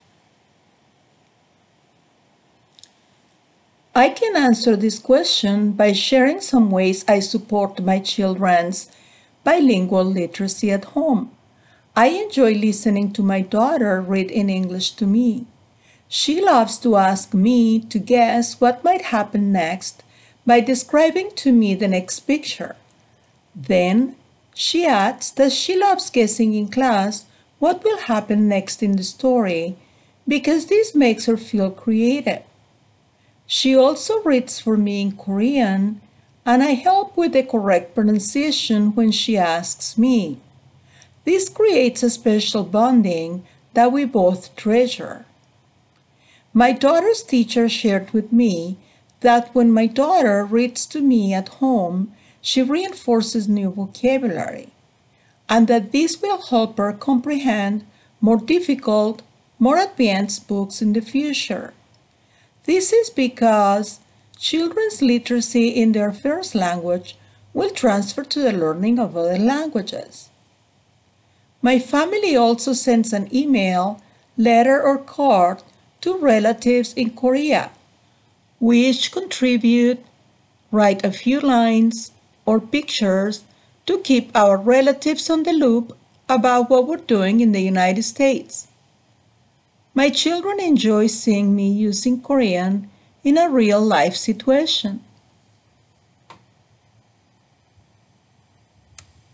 [Note: In the transcript below, ellipses indicate that the speaker paused.]
The response effectively communicates clear and logically sequenced ideas delivered with a consistent flow of speech, few pauses, intelligible pronunciation, and appropriate intonation.
Examples of such errors include some inconsistency in the flow of speech and few pauses.